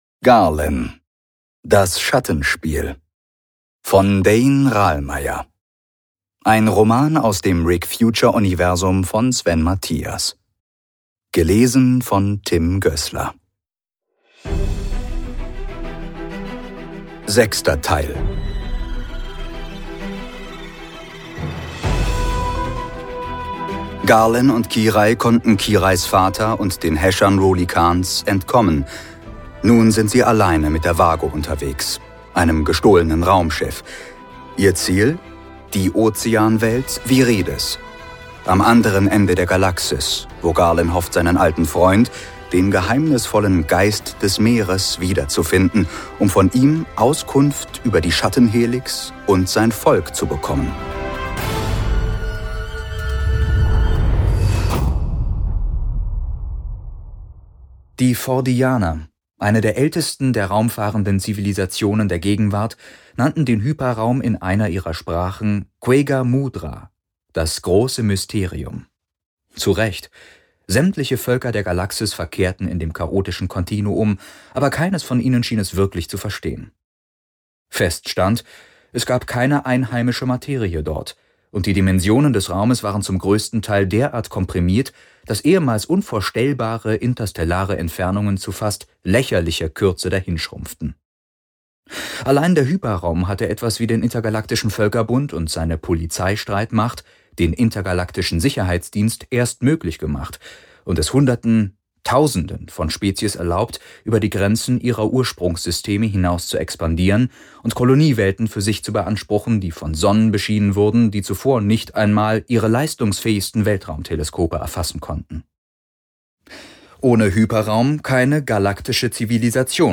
Die sechste Folge aus der 10-teiligen Podcastveröffentlichung unseres Hörbuches